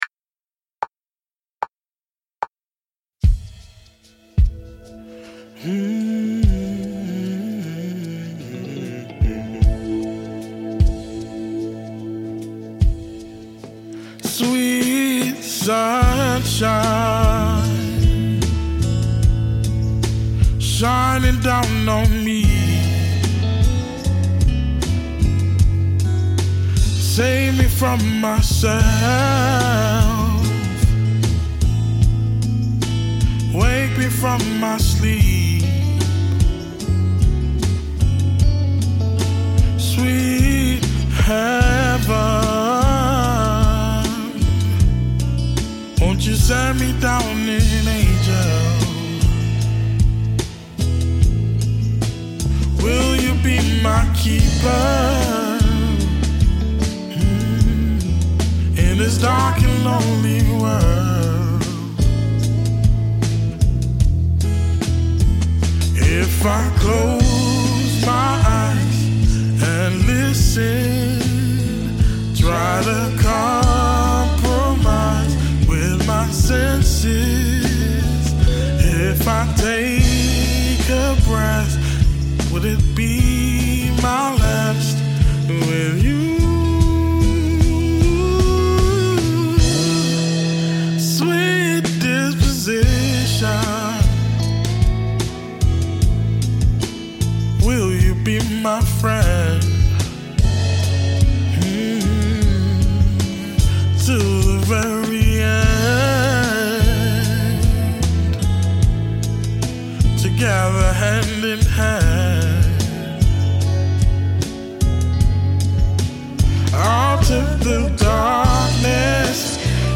Bitte beachtet, dass dies nur ein Arbeitsmix ist, also in keinster Weise final.
Lead Vocals
Gitarre
Drums//Percussion